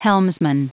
Prononciation du mot helmsman en anglais (fichier audio)
Prononciation du mot : helmsman